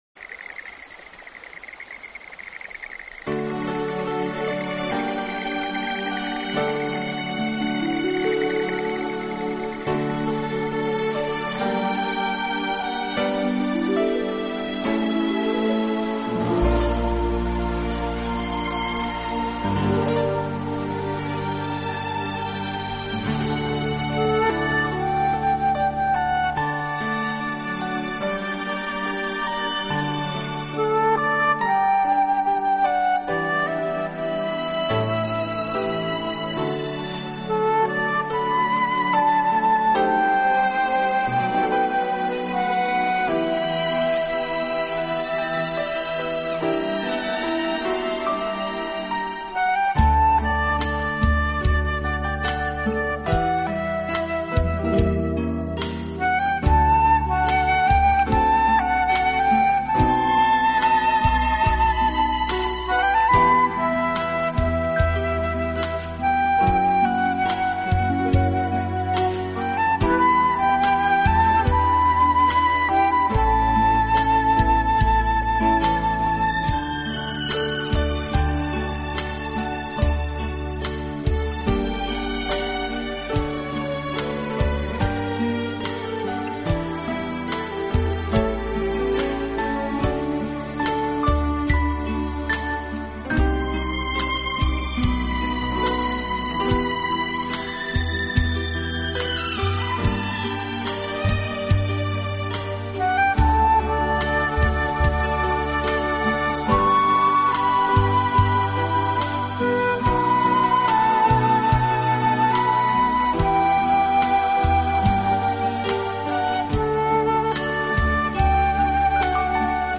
专辑歌手：纯音乐
在优雅的音乐中，在清新的大自然声响里，